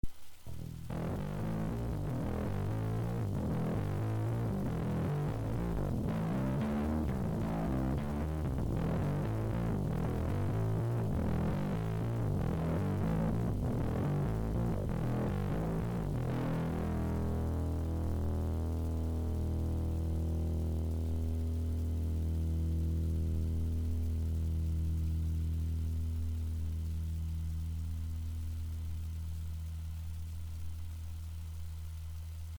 ただ、こちらはTUBEモード。DRIVEはほとんどフル。